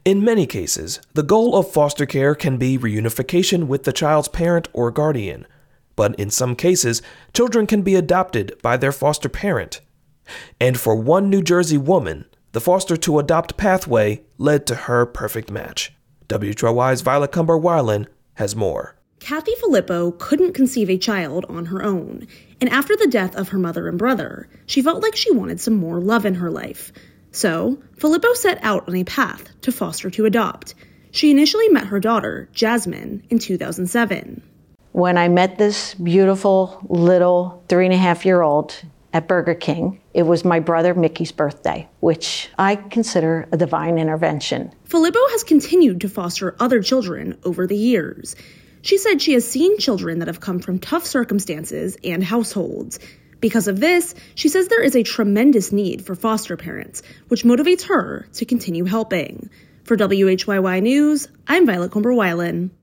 Protesters and constituents crowded New Jersey Republican Rep. Leonard Lance’s town hall meeting, at times shouting and booing.